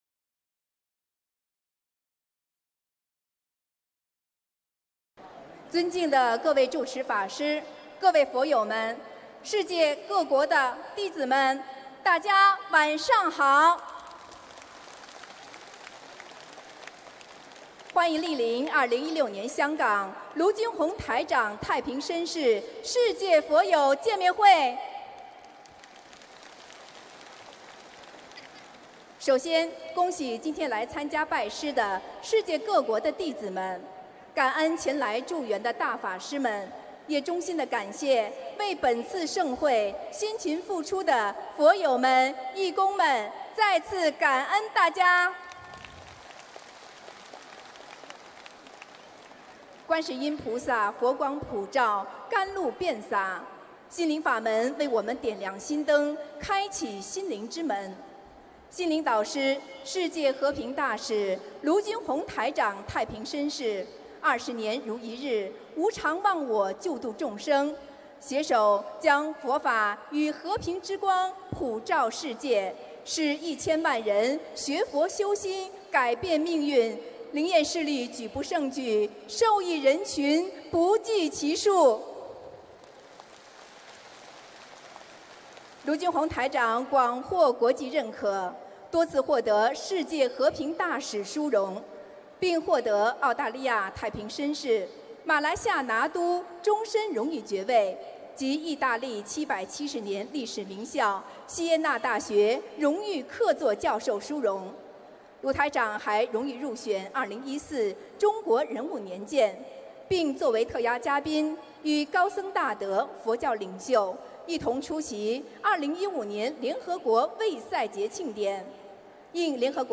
2016年7月4日中国香港见面会开示（视音文） - 2016年 - 心如菩提 - Powered by Discuz!